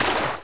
waterrun1.wav